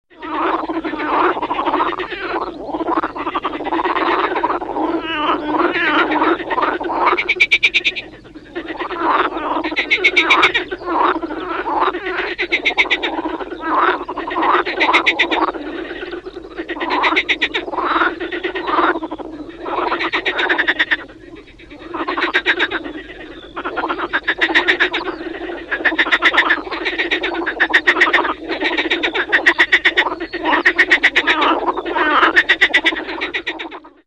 Другие рингтоны по запросу: | Теги: жаба, Лягушка, Frogs
Категория: Различные звуковые реалтоны